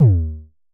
Festival Kicks 16 - D#2.wav